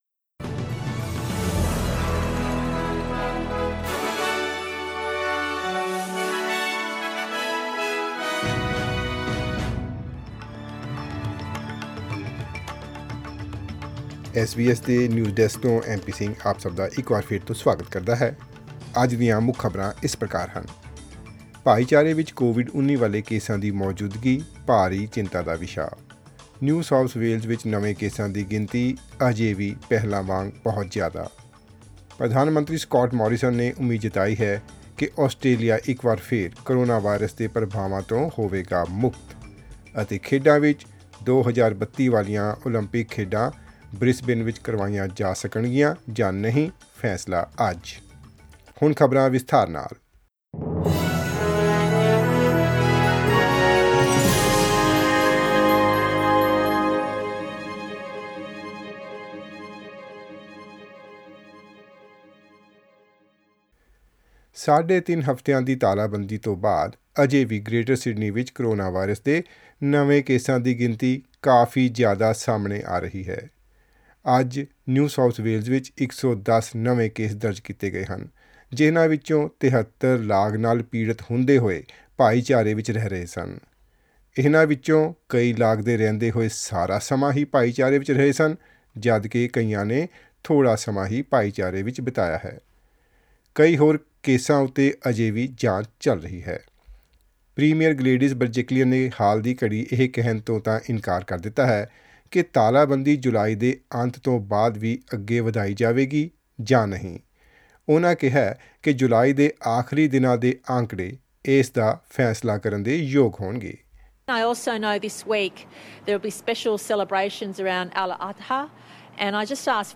Listen to the latest news headlines in Australia from SBS Punjabi radio.
Click on the player at the top of the page to listen to the news bulletin in Punjabi.